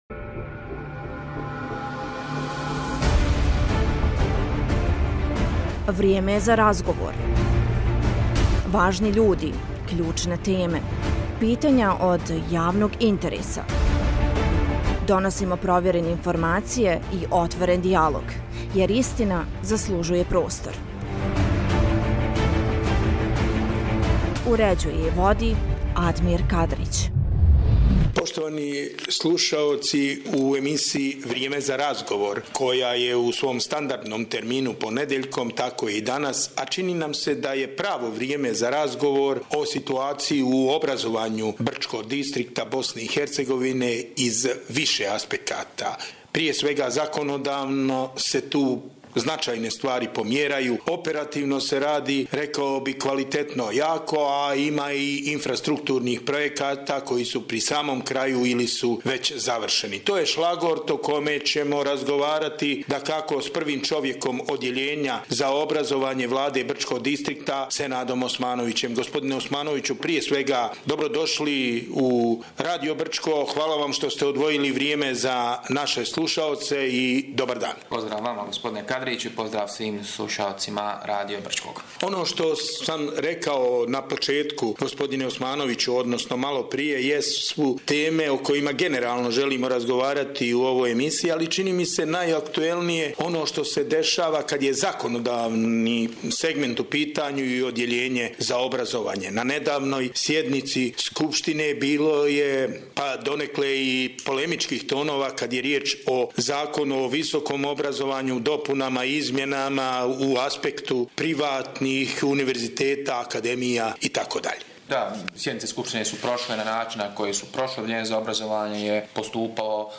Gost emisije “Vrijeme za razgovor” bio je Senad Osmanović, šef Odjeljenja za obrazovanje brčanske Vlade, sa kojim smo razgovarali o aktuelnom trenutku, obazovnog sistema Distrikta. U emisiji su pored ostalog analizirane najnovije izmjene i dopune Zakona o visokom obrazovanju, kao i Zakona o osnovnim i srednjim školama u Brčko distriktu BiH i šta oni donose.